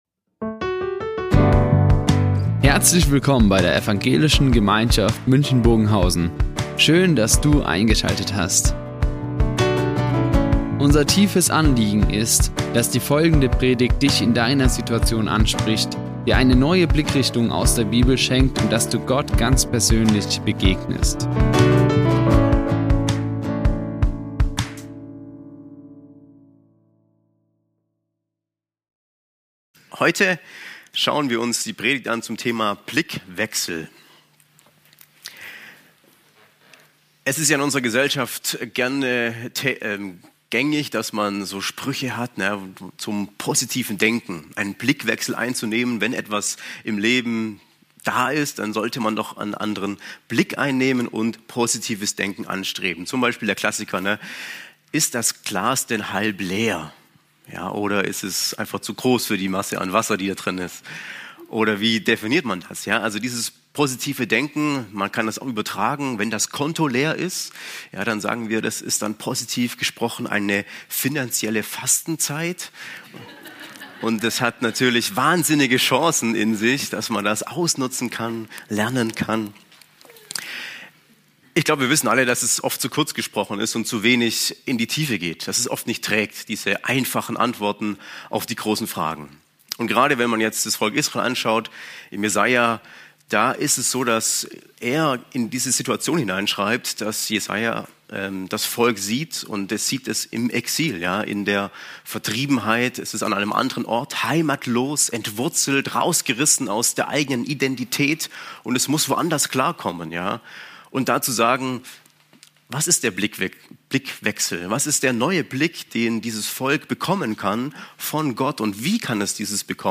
26-31 zum Thema "Blickwinkel" Die Aufzeichnung erfolgte im Rahmen eines Livestreams.